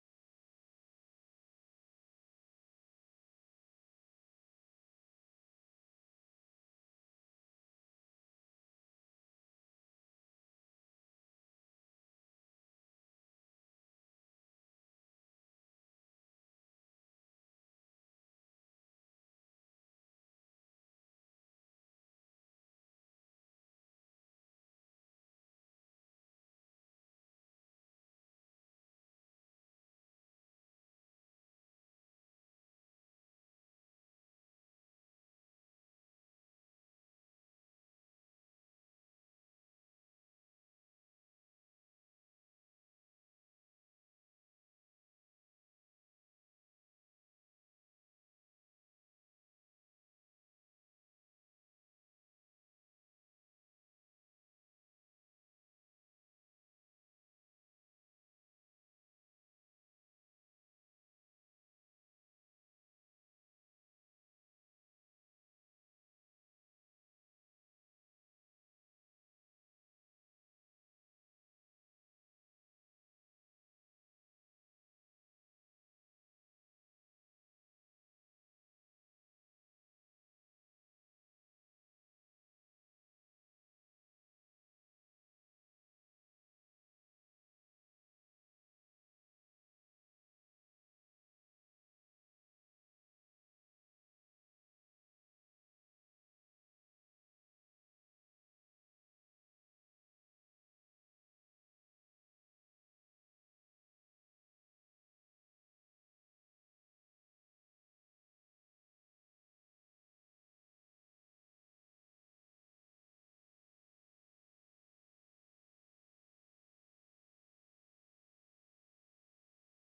Waging Peace part 1 Sermon